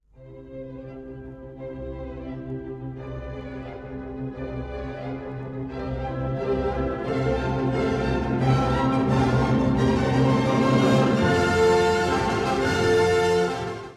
そして、バイオリンのちいさな動機が徐々に力を増し…
英雄を讃えるかのようなクライマックスを築き上げます。